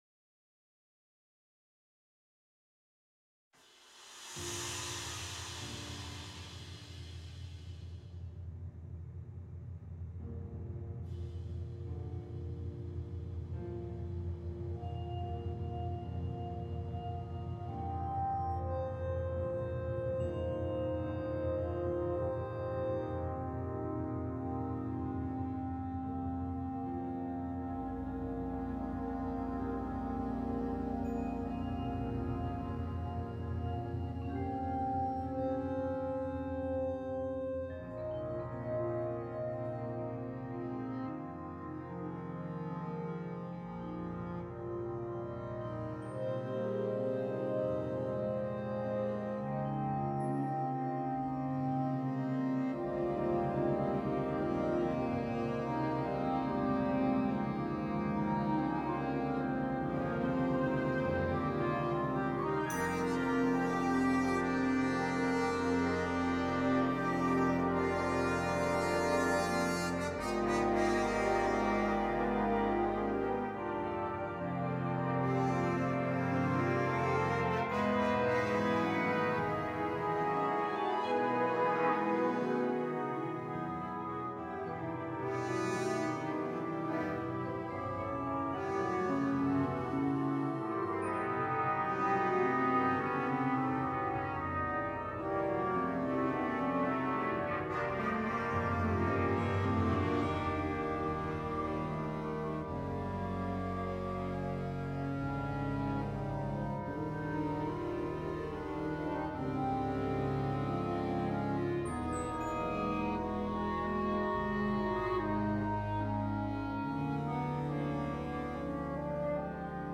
obra para banda sinfónica